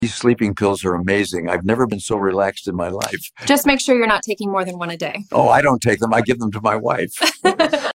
💀💍 AI skits out here capturing peak grandpa humor — dark, petty, and straight to the point.